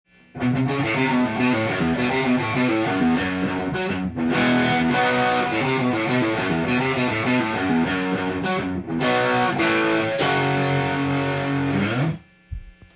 譜面通り弾いてみました
このリフの素晴らしいところは、半音階が入ったりコードが入ったりするところです。